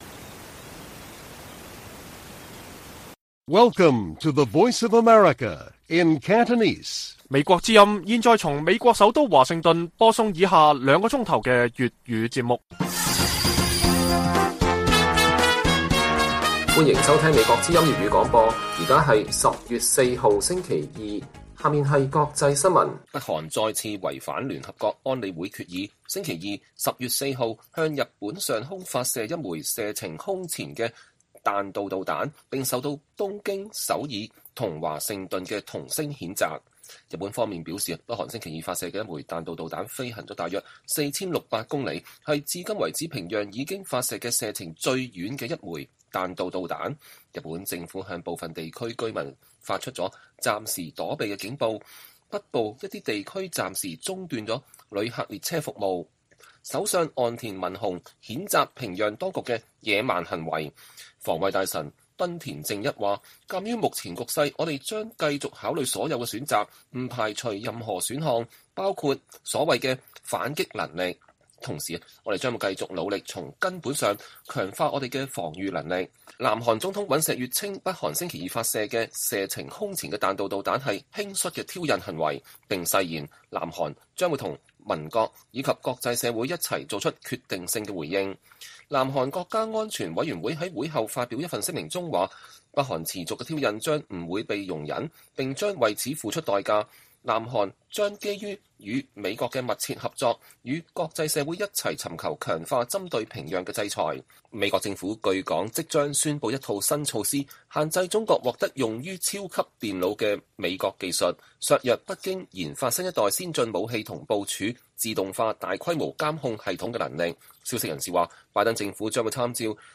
粵語新聞 晚上9-10點: 北韓時隔5年後再向日本上空發射彈道導彈